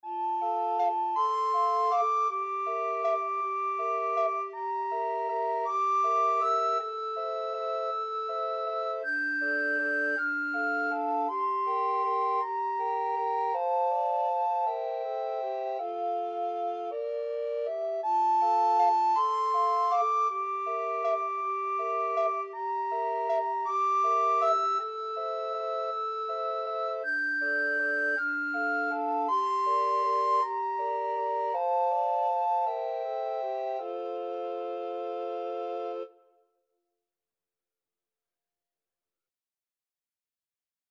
Free Sheet music for Recorder Quartet
Soprano RecorderAlto RecorderTenor RecorderBass Recorder
F major (Sounding Pitch) (View more F major Music for Recorder Quartet )
3/4 (View more 3/4 Music)
Allegretto = 160
Classical (View more Classical Recorder Quartet Music)